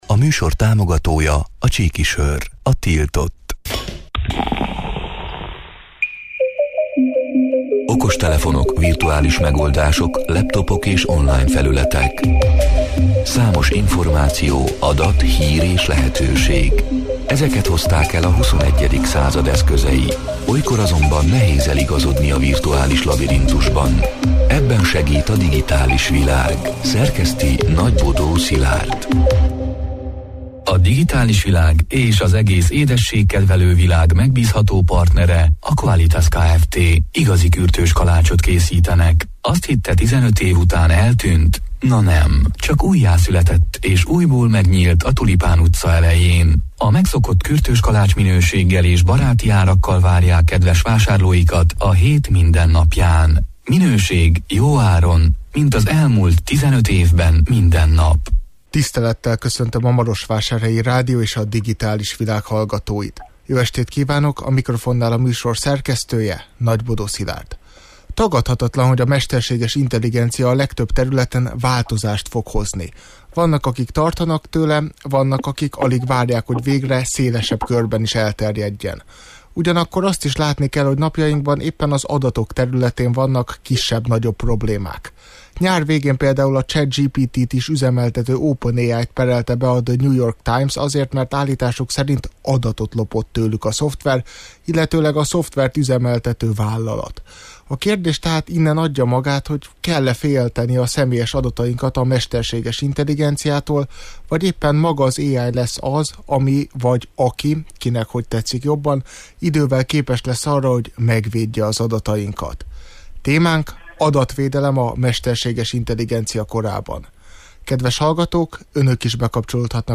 A Marosvásárhelyi Rádió Digitális Világ (elhangzott: 2023. október 10-án, kedden este nyolc órától élőben) c. műsorának hanganyaga: Tagadhatatlan, hogy a mesterséges intelligencia a legtöbb területen változást fog hozni.